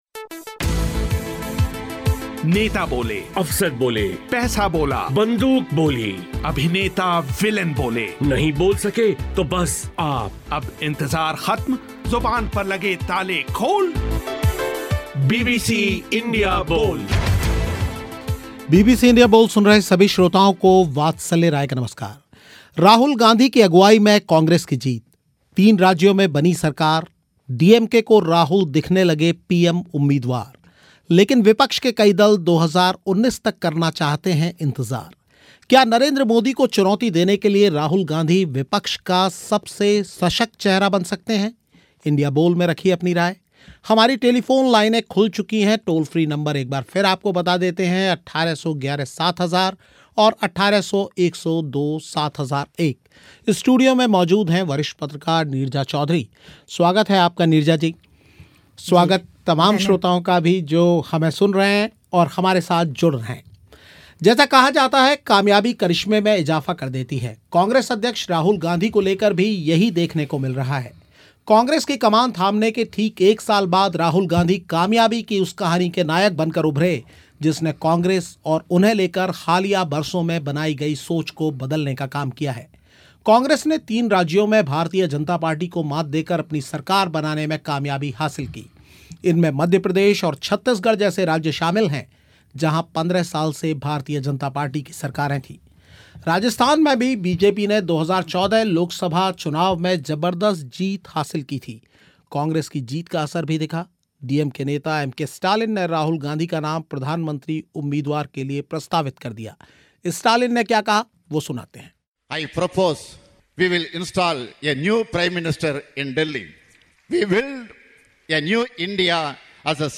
श्रोताओं ने भी रखी अपनी बात